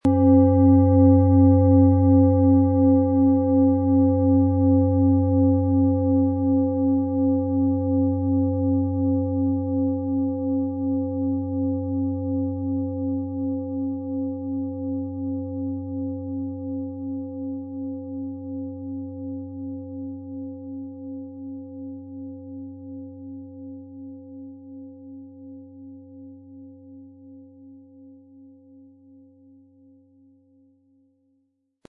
Planetenschale® Für die Welt öffnen & Beschützt fühlen mit OM-Ton & Biorhythmus Geist, Ø 26 cm, 1600-1700 Gramm inkl. Klöppel
Es ist eine von Hand geschmiedete Klangschale, die in alter Tradition in Asien von Hand gefertigt wurde.
• Tiefster Ton: Biorhythmus Geist
Sie möchten den Original-Ton der Schale hören? Klicken Sie bitte auf den Sound-Player - Jetzt reinhören unter dem Artikelbild.
MaterialBronze